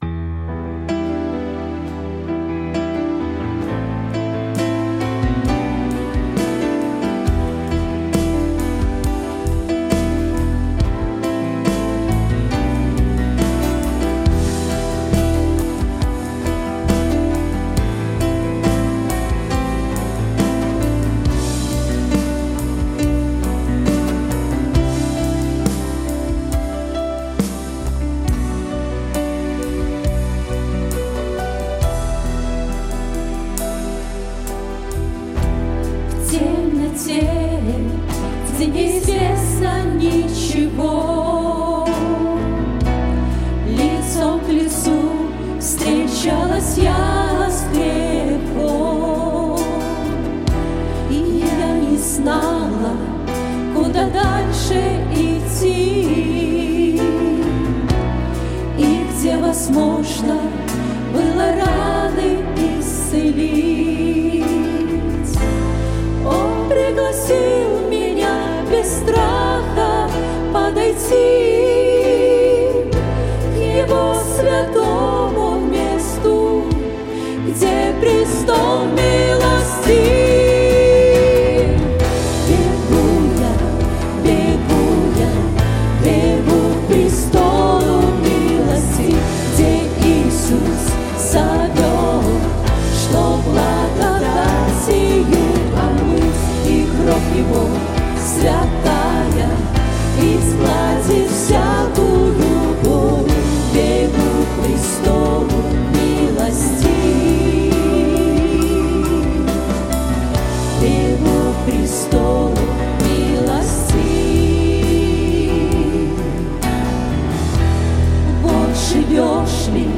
Псалмы группы
На протяжении всех лет, со дня основания церкви, была основана группа, где всегда были люди, которые служили Богу пением, и игрой на музыкальных инструментах.